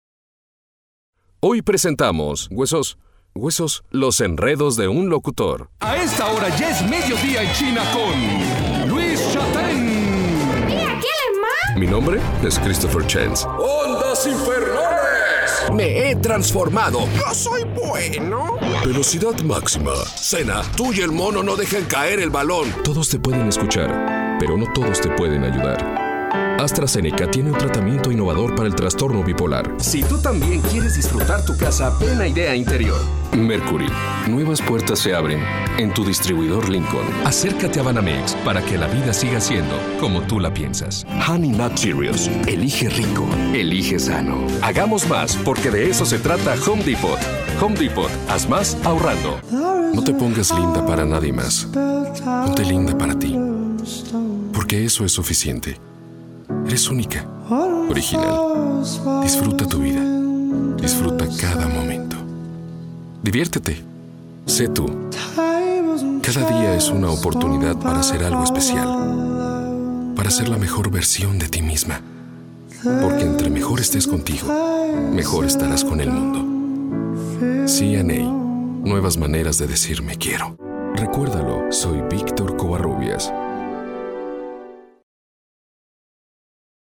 Actor, director, locutor
Locutor
He prestado mi voz para diferentes marcas e instituciones como: